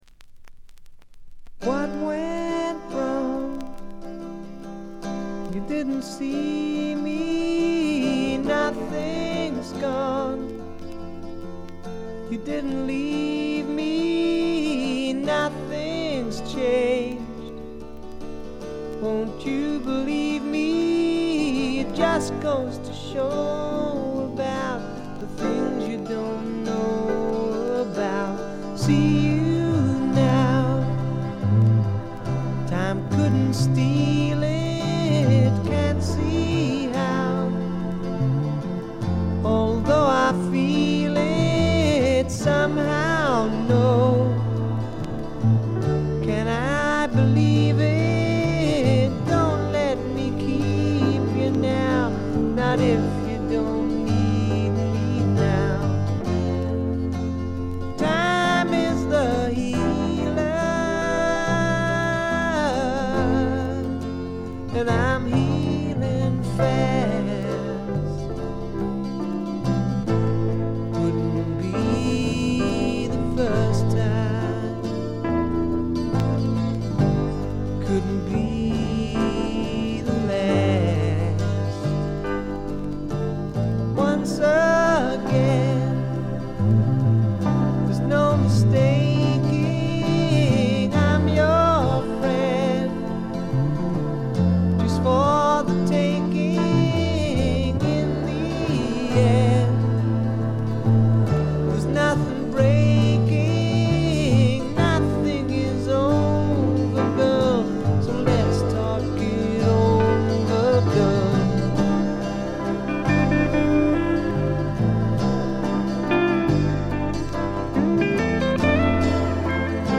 部分試聴ですが、軽微なチリプチ、バックグラウンドノイズ程度。
初期のマッギネス・フリントのようなスワンプ路線もありますが、それに加えて激渋ポップ感覚の漂うフォークロック作品です。
それにしてもこの人の引きずるように伸びのあるヴォーカルは素晴らしいでね。
試聴曲は現品からの取り込み音源です。